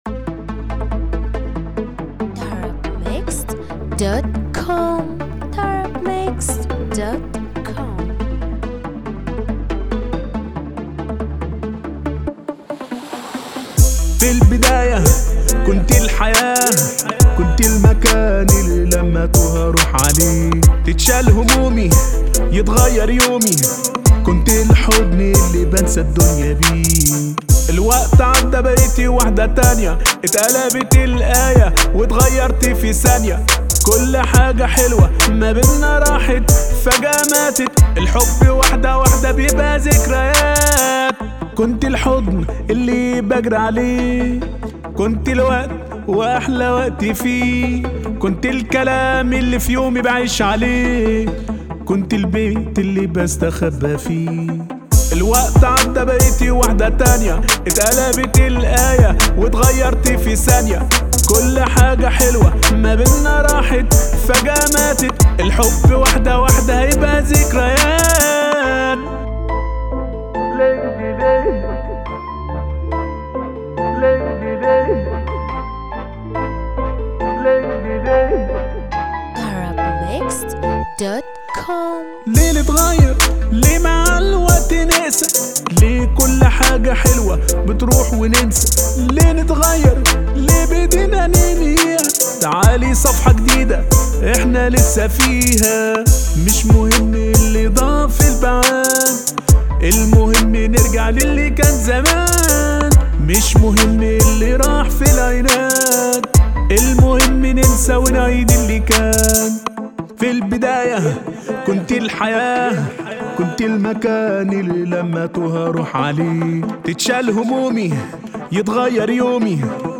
• النوع : shobeiat